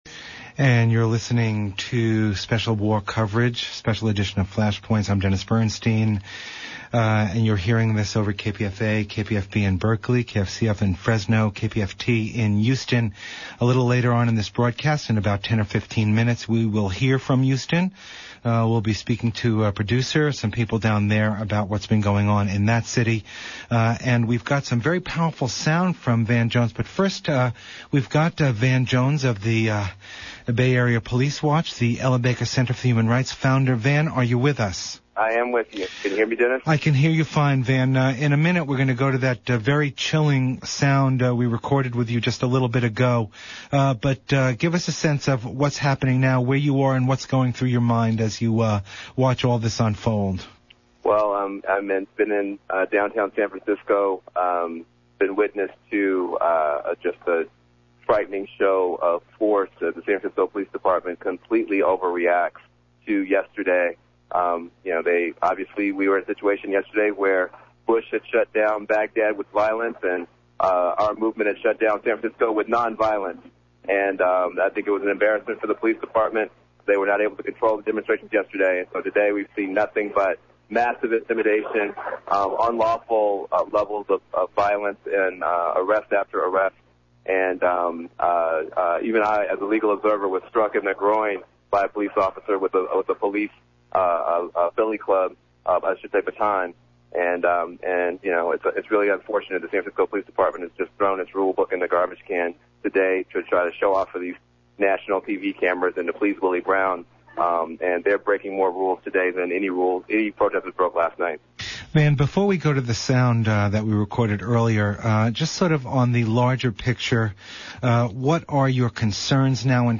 Van Jones phone interviews on KPFA/Pacifica, FLASHPOINTS
He provides live witness via phone. He notes that police are using strong-arm tactics today because they were essentially caught with their pants down yesterday and taking heat from the mayor, etc.
Aired on KPFA in the 2pm hour.